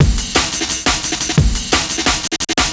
cw_amen14_175.wav